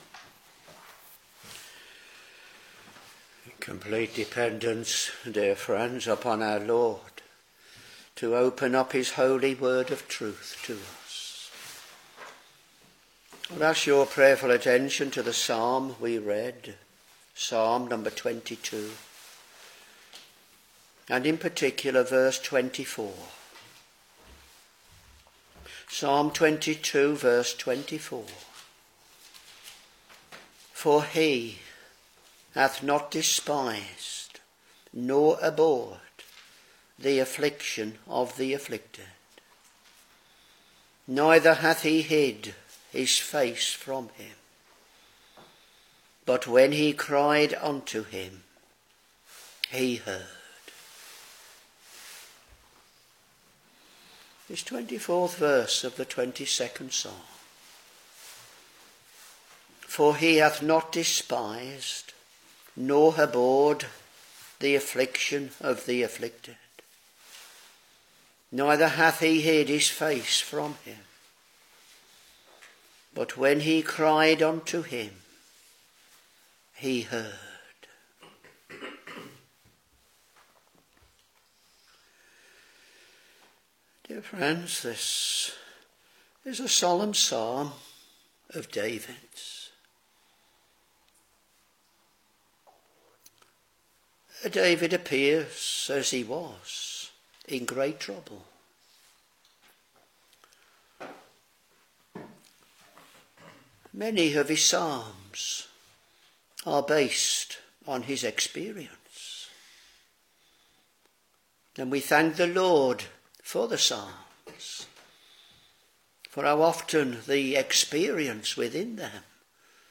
Back to Sermons Psalm 22 v.24